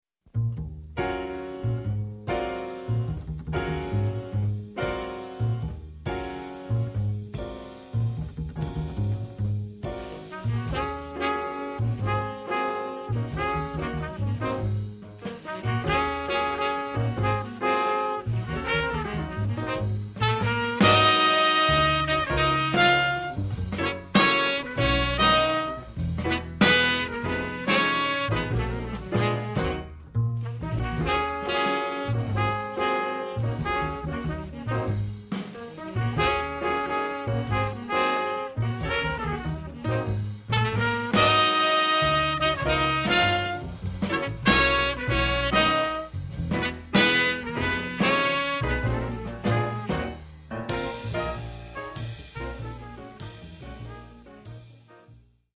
Swing (medium)